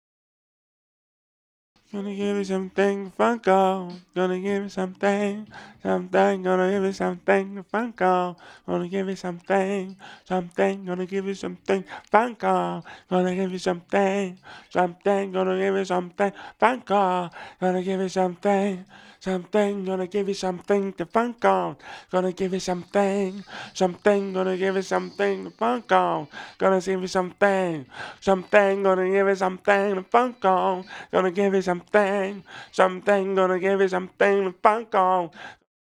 DF_107_E_FUNK_VOX_03 .wav